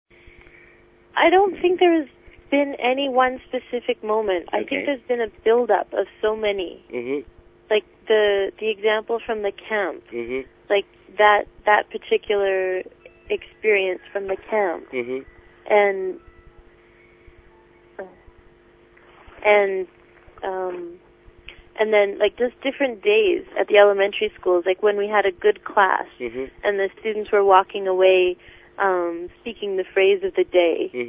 インタビュー音声公開&クイズ